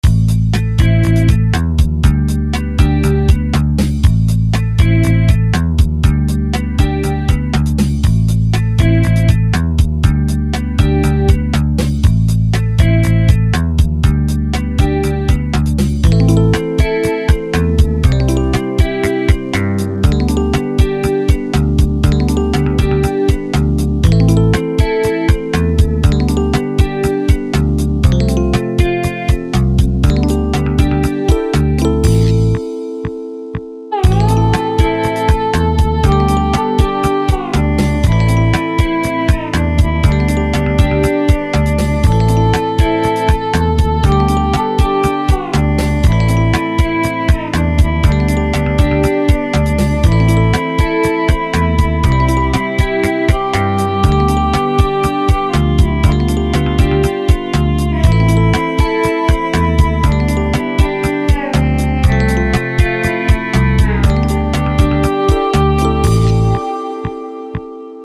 Скачать "Не со мной"минус
там с 35 секунды идет проигрышь
При минимуме инструментов такое насыщенное звучание...